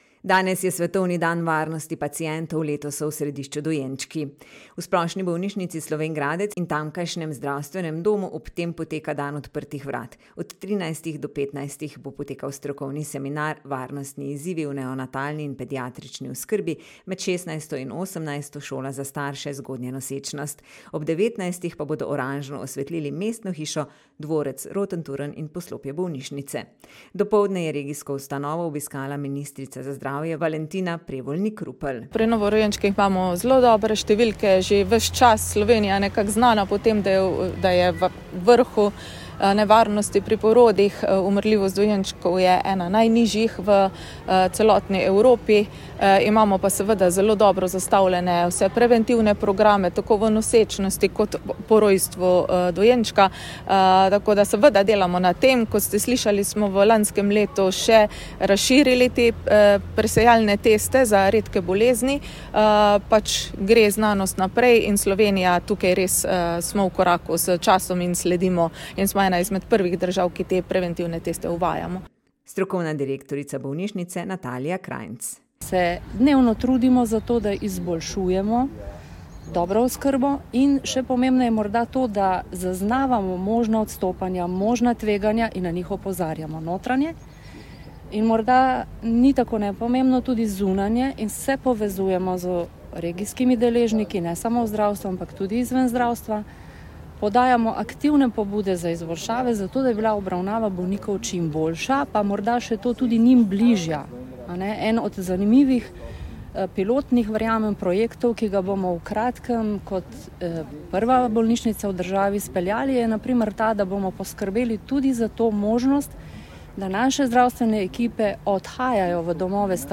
Dopoldne je regijsko ustanovo obiskala ministrica za zdravje Valentina Prevolnik Rupel